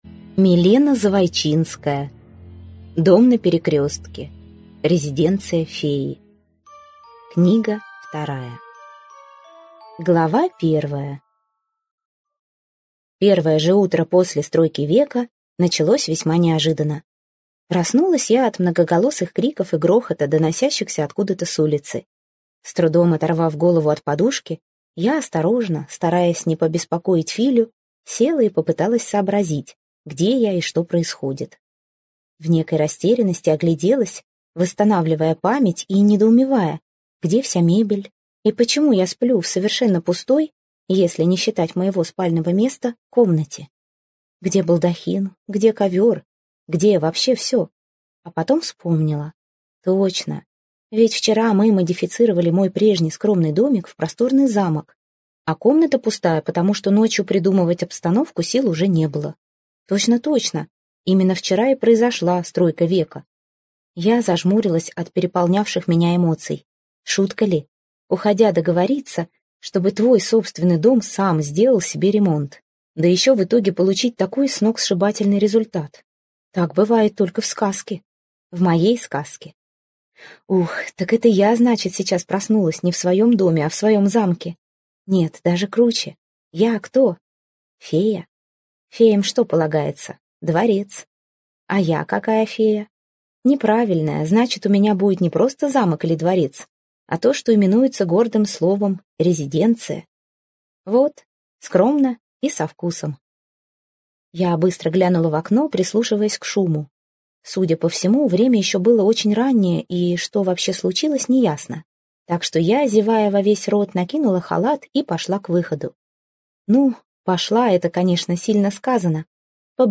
Аудиокнига Дом на перекрестке. Резиденция феи - купить, скачать и слушать онлайн | КнигоПоиск